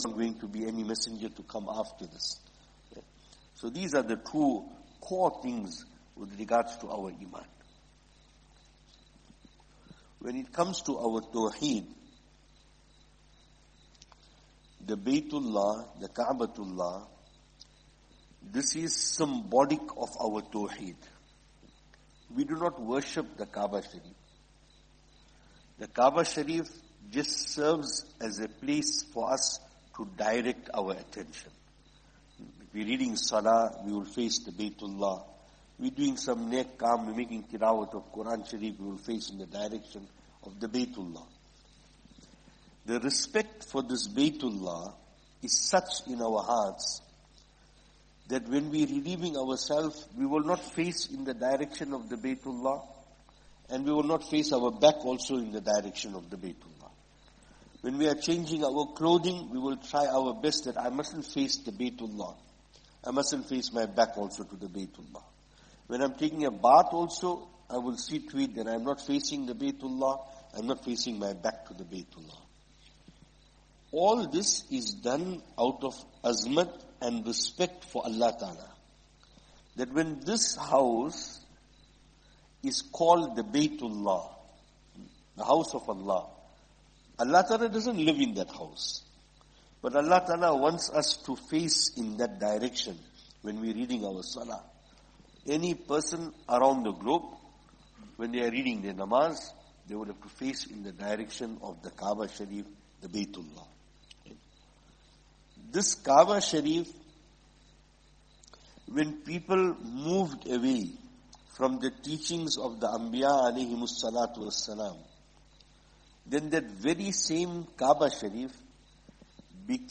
Programme from Musjid Rabia, Estcourt